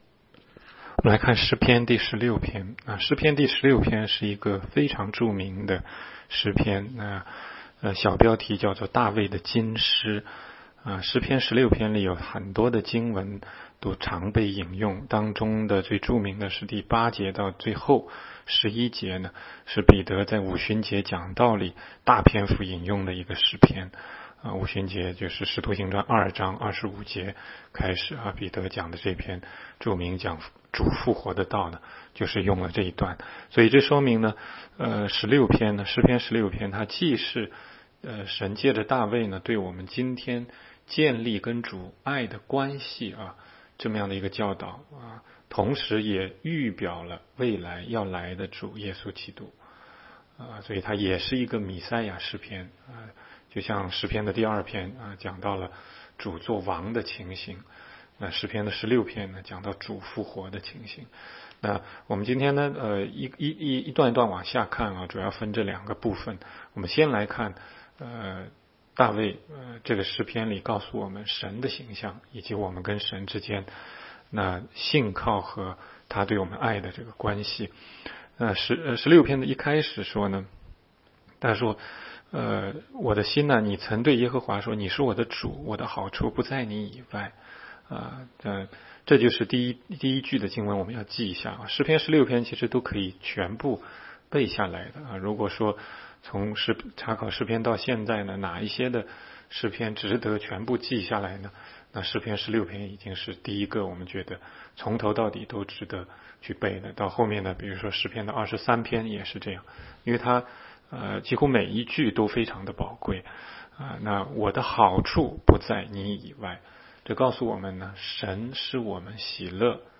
16街讲道录音 - 每日读经-《诗篇》16章